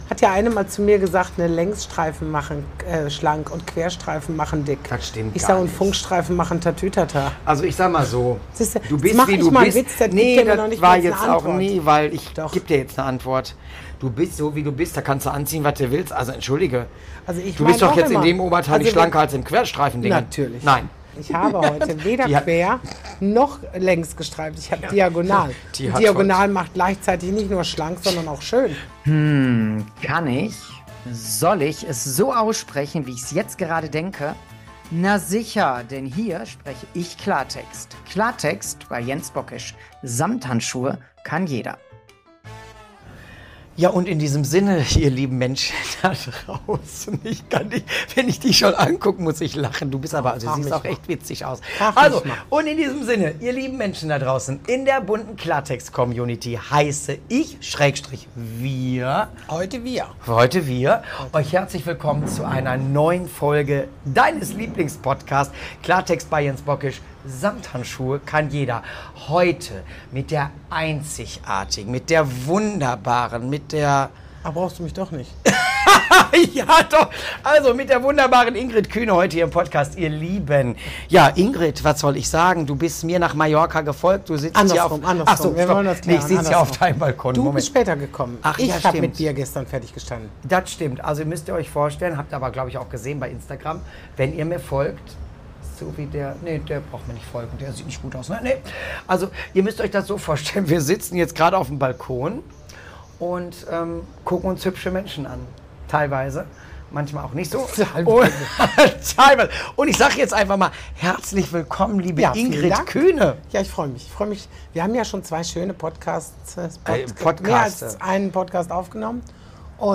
Sie wurde spontan und ohne Technik-Tamtam direkt auf dem Hotelbalkon auf Mallorca aufgenommen. Der Ton ist nicht Studio-perfekt, aber dafür ist die Stimmung umso echter.
Zwei Mikrofone (naja, eher eins), zwei Stimmen, zwei Meinungen und jede Menge ehrlicher Klartext.